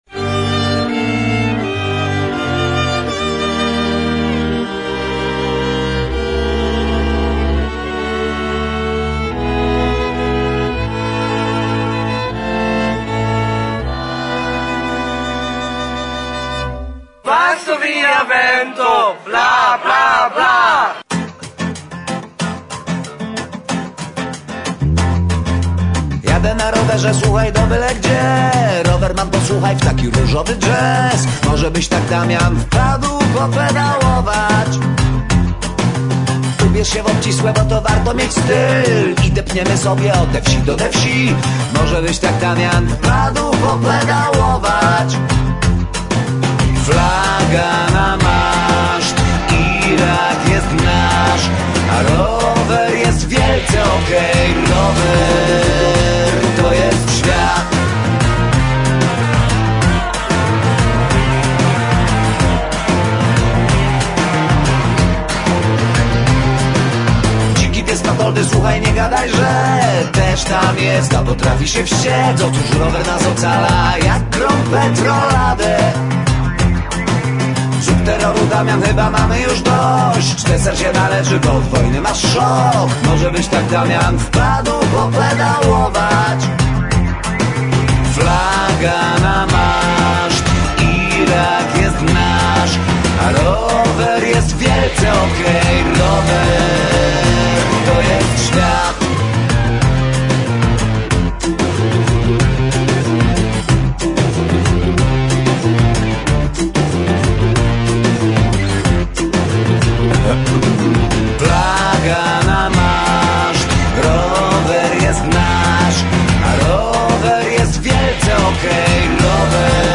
Intervjuo